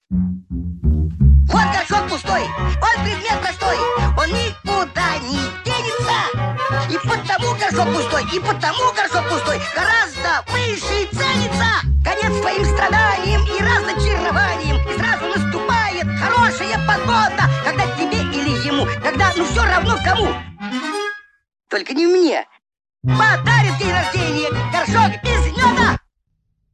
Мишка поет о том, что видит перед собой, и это прекрасно.